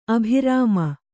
♪ abhirāma